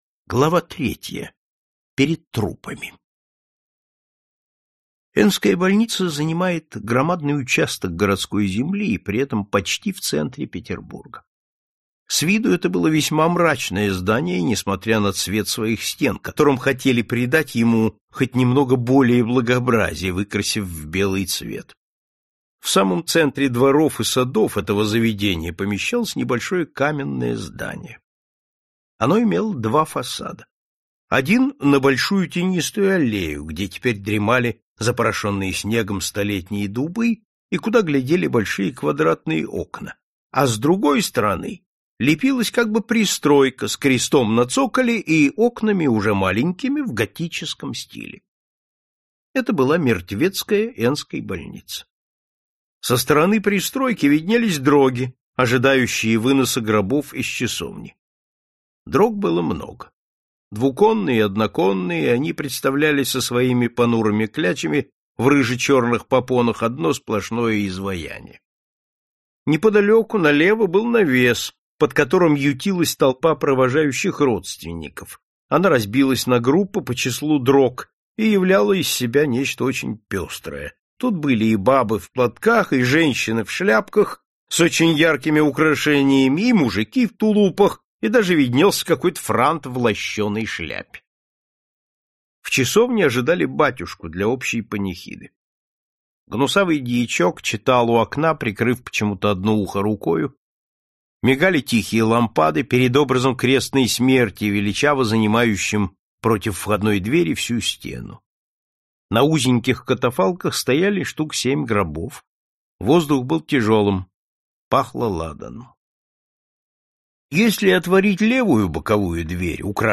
Аудиокнига Тайна угрюмого дома | Библиотека аудиокниг